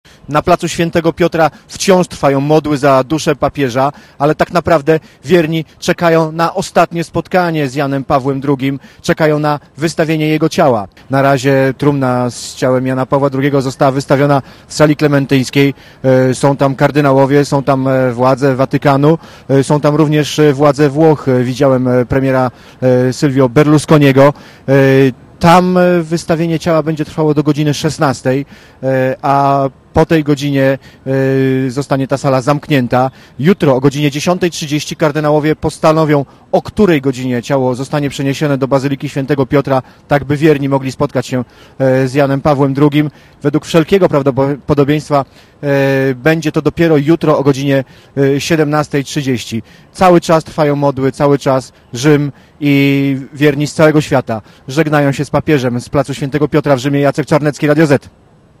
plac-papiez.mp3